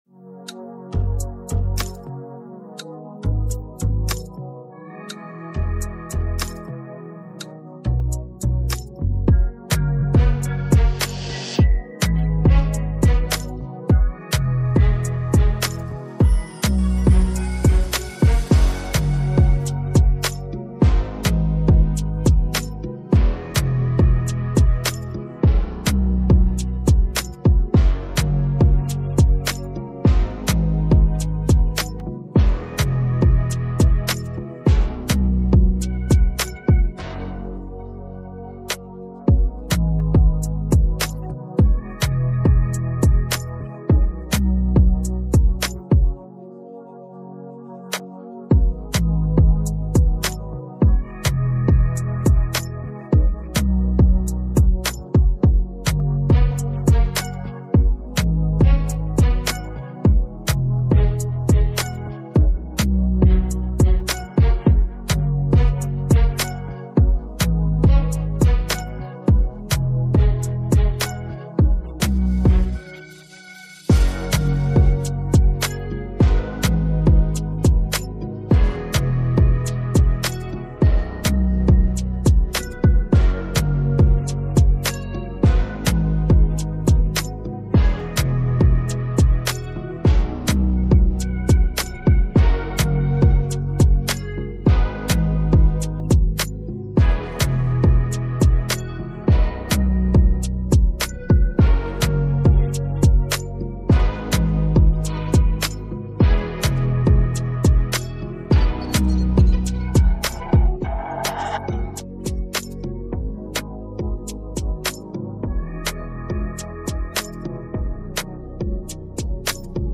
Afro dancehall Afrobeats
free beat